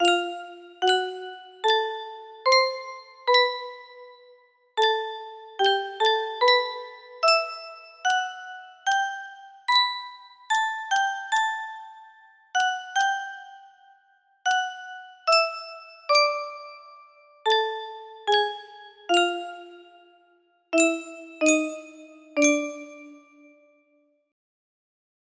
Full range 60
but Music Box version!!